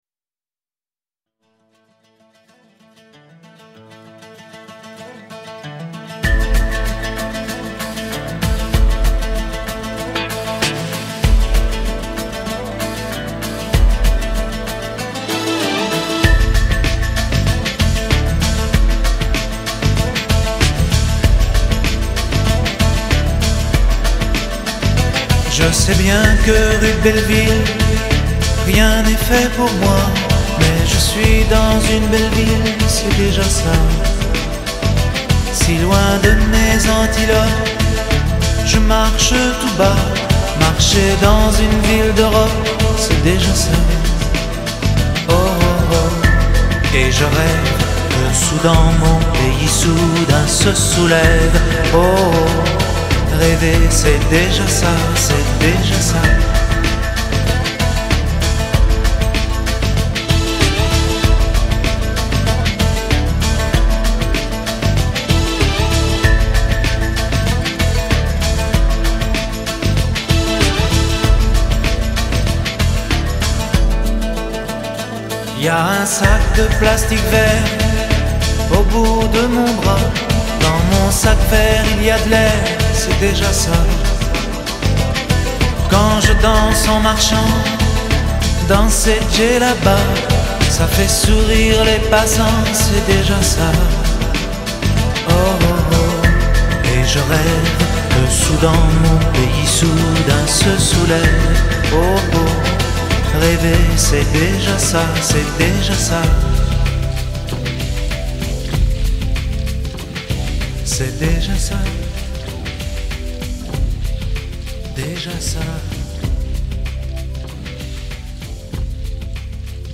tonalité DO majeur